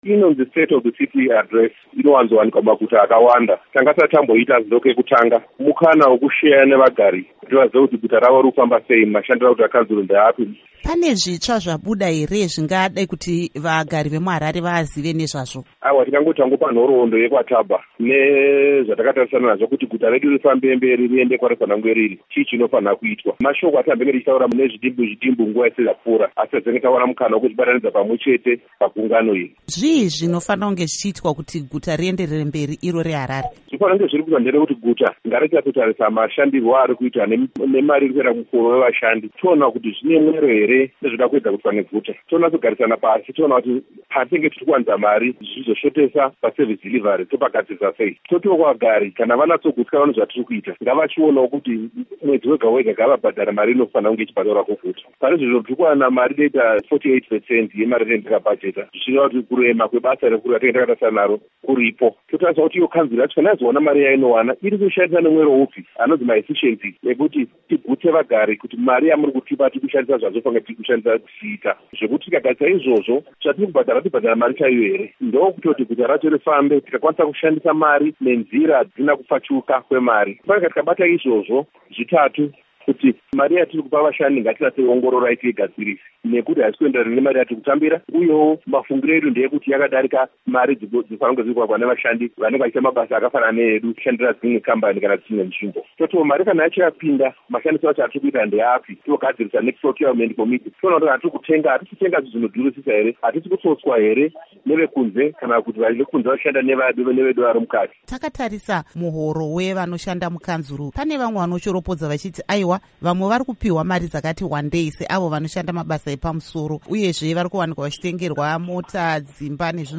Hurukuro NaVa Bernard Manyenyeni